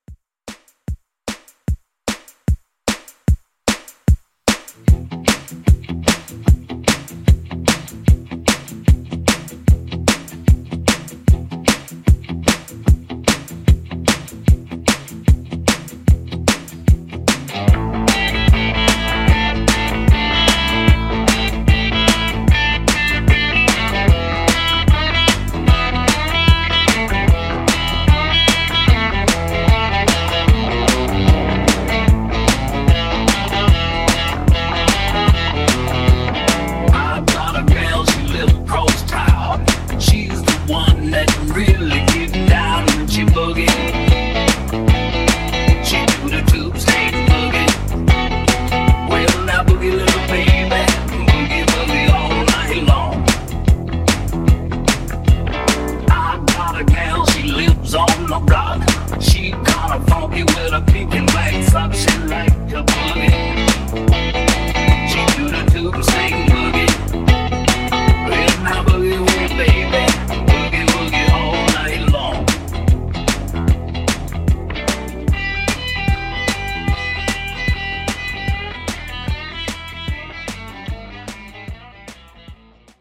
Genre: 60's
Clean BPM: 135 Time